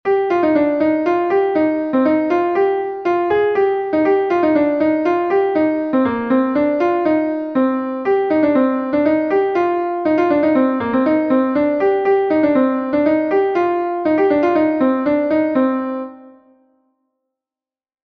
Gavotenn Seglian est un Gavotte de Bretagne